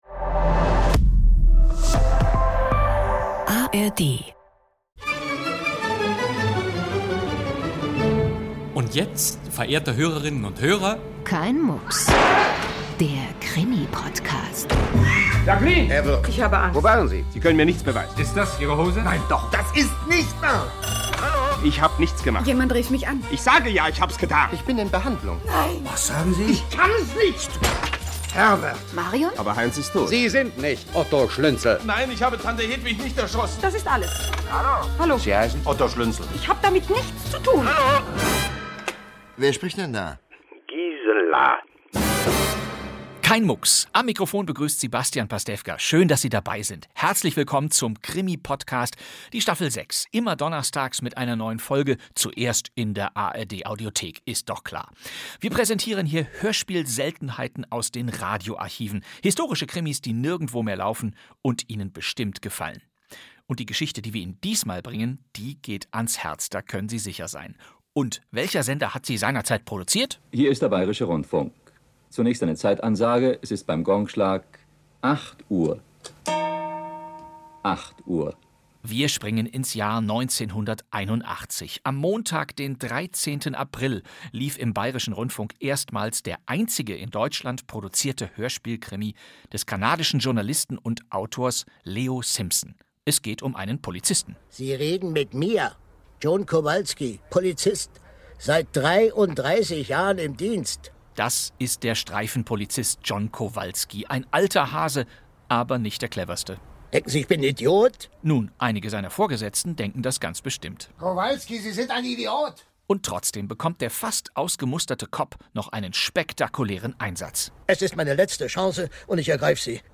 Bastian Pastewka präsentiert die 79. Folge der legendären Jagd-nach-dem-Täter-Reihe des NDR.
Audio Drama